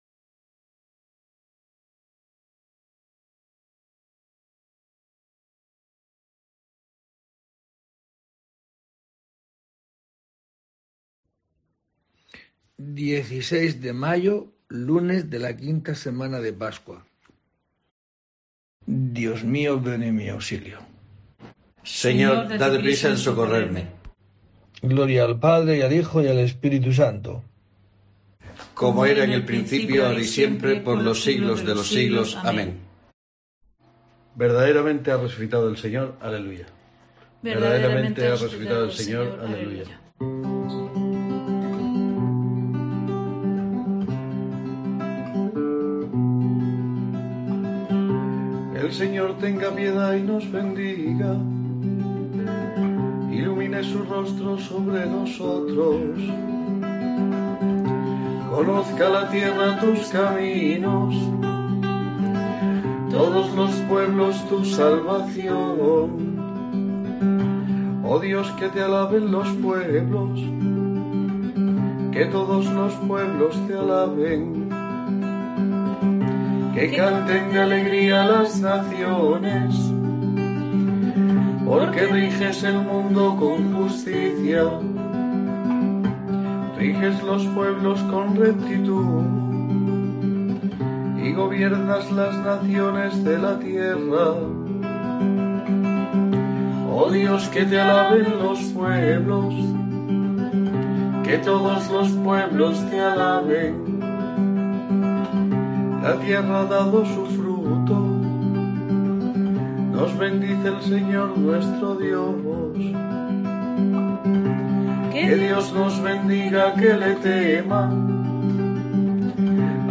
16 de mayo: COPE te trae el rezo diario de los Laudes para acompañarte